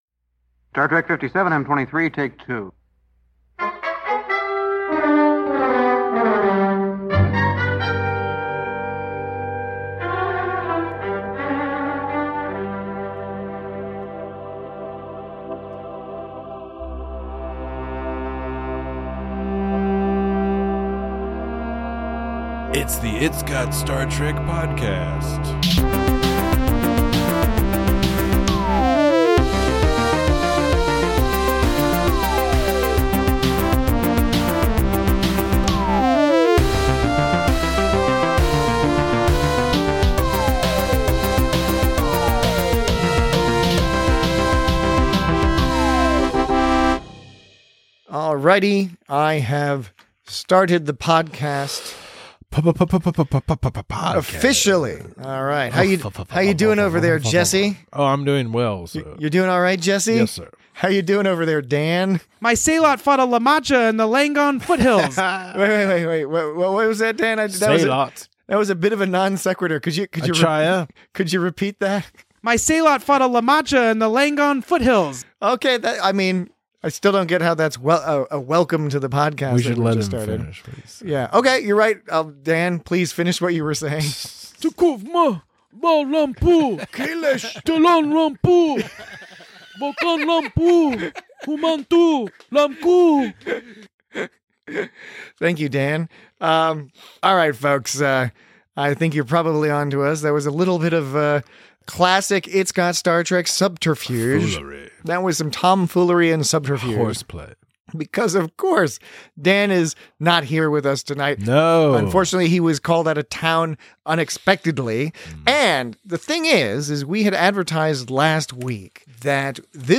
Join two-thirds of your regular clutch of hosts as they take a wander down memory lane while listening to highlight clips from old episodes of It's Got Star Trek!